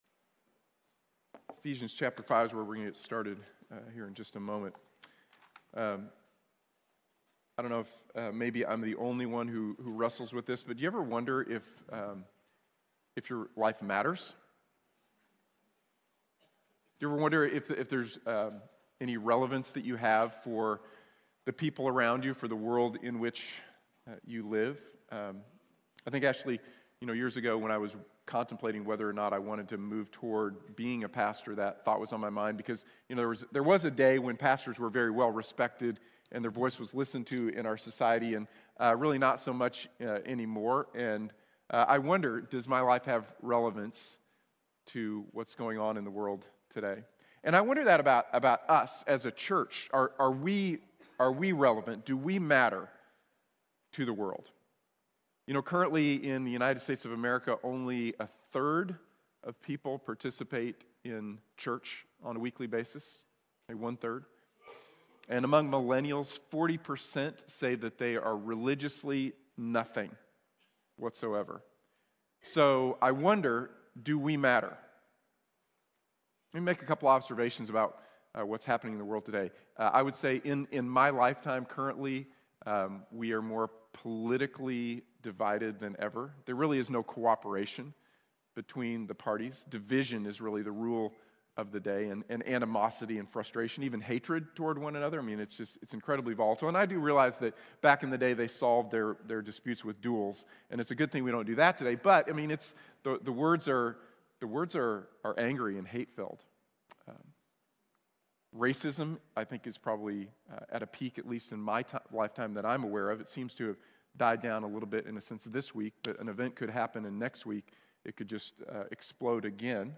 We Are the Church | Sermon | Grace Bible Church